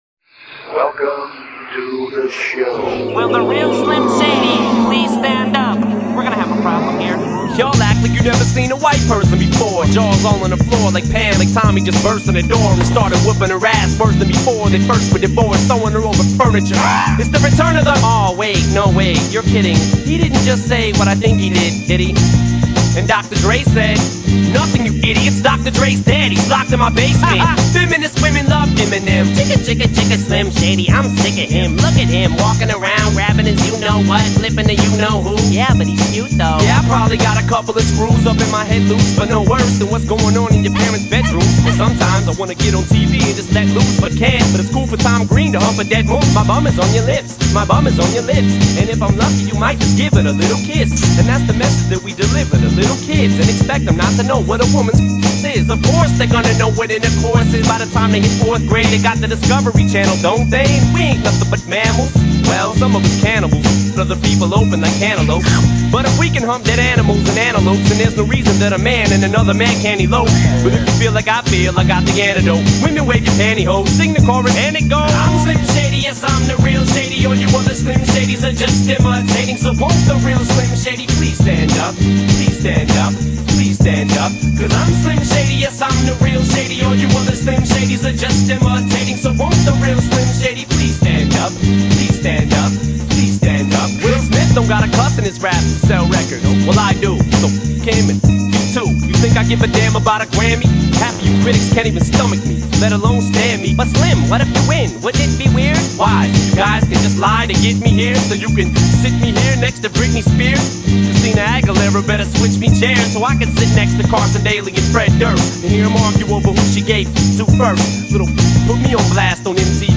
mashup mix remix